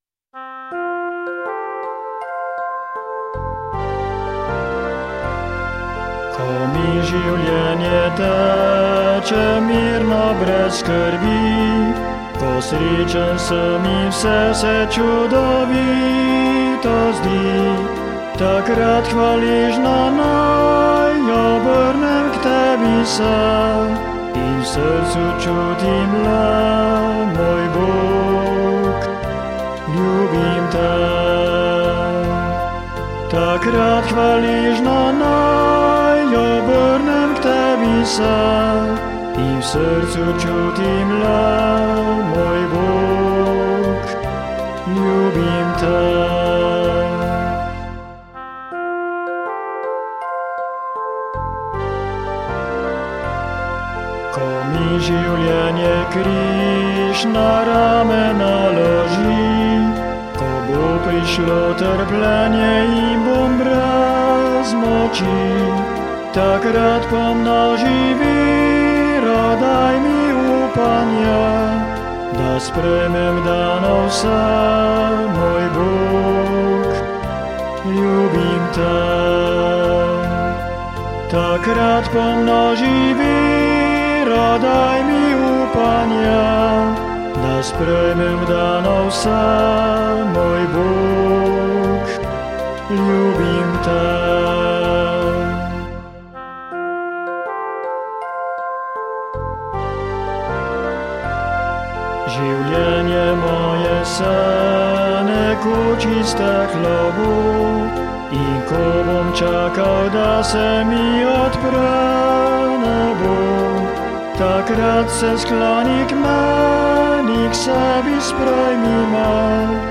Posnetek  : DEMO 2 (za predstavitev, vaje...)
MP3  (demo)